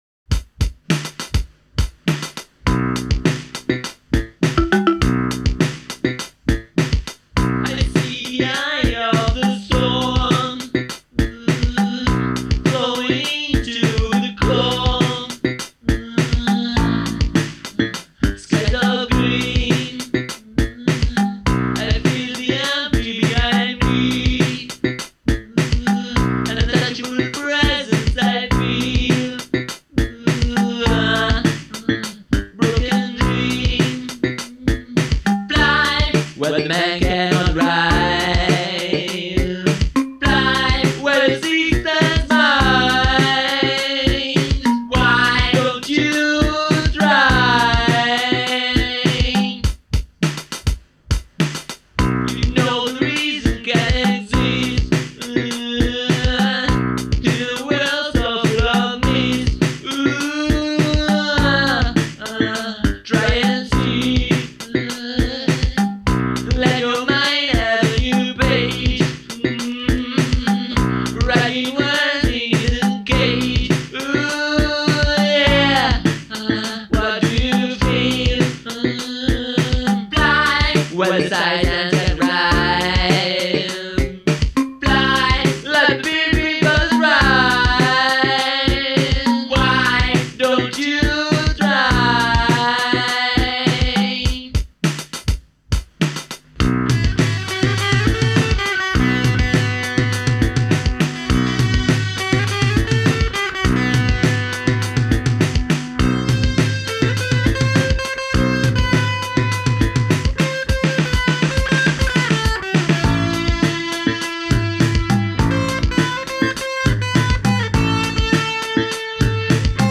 voce, cori, tastiere, chitarre e programmazione.